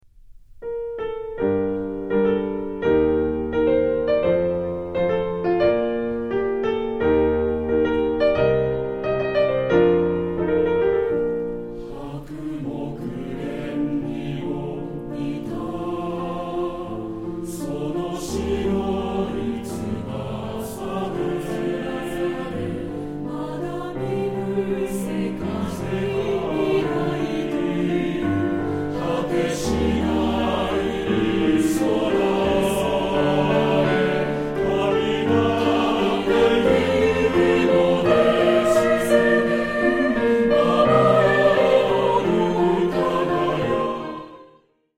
混声3部合唱／伴奏：ピアノ